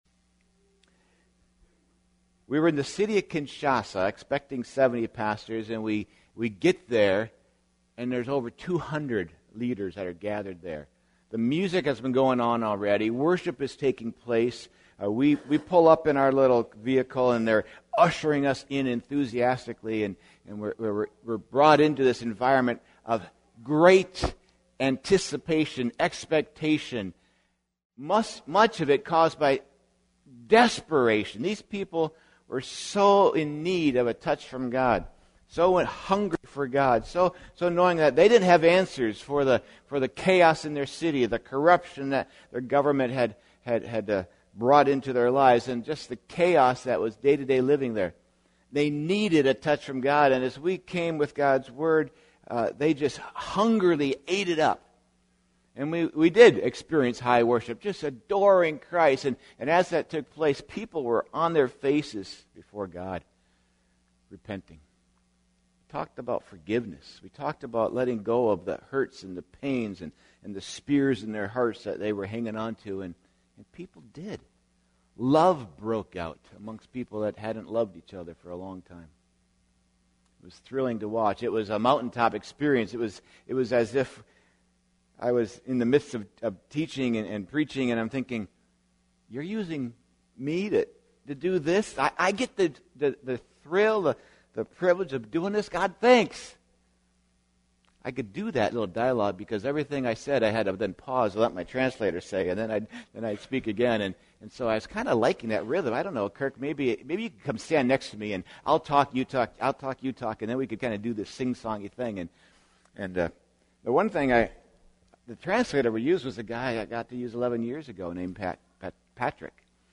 Series: Sunday Service Topic: Humility